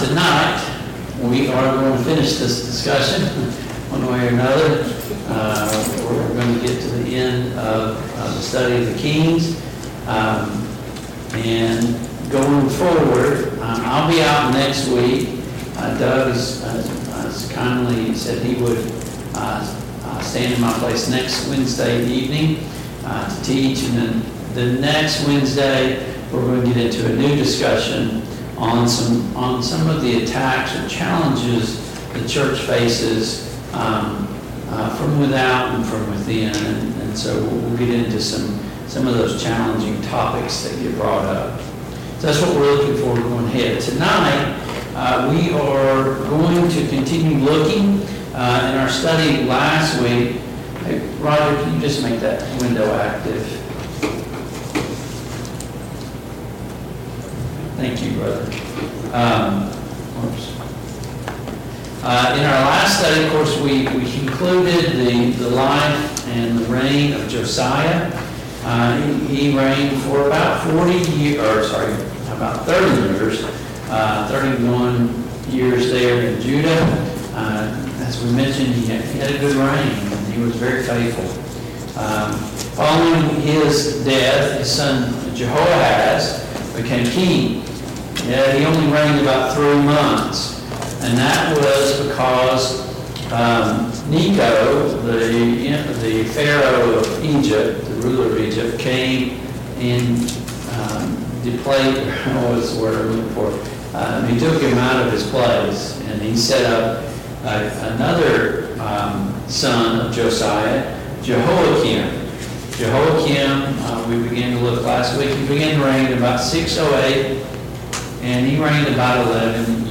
The Kings of Israel and Judah Passage: 2 Kings 24, 2 Kings 25, 2 Chronicles 35, 2 Chronicles 36 Service Type: Mid-Week Bible Study